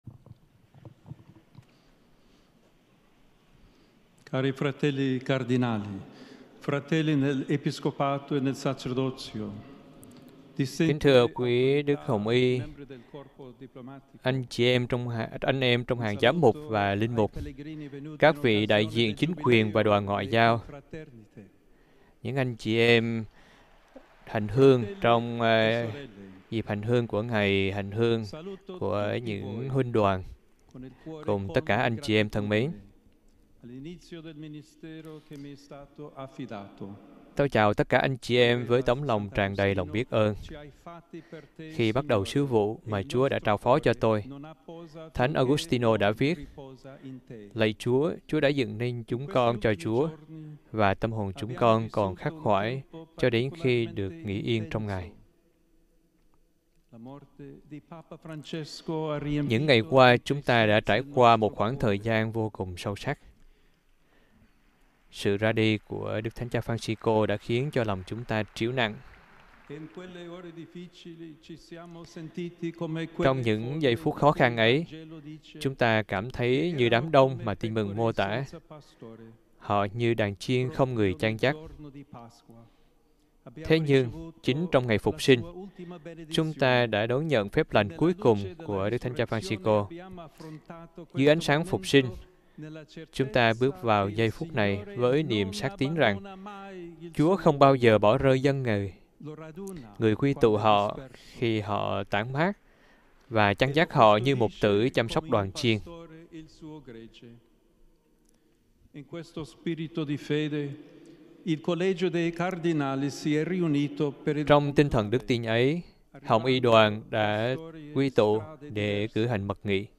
Bài giảng Thánh Lễ khai mạc sứ vụ của ĐTC Lêô XIV (Chúa Nhật 18/5/2025)
Bai-giang-Thanh-Le-khai-mac-su-vu-cua-DGH-Leo-XIV-Chua-Nhat-18-5.mp3